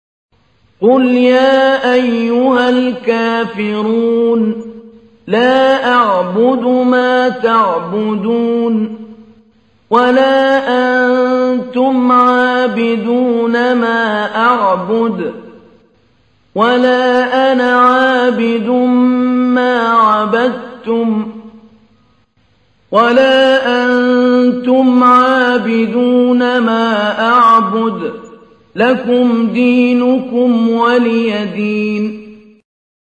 تحميل : 109. سورة الكافرون / القارئ محمود علي البنا / القرآن الكريم / موقع يا حسين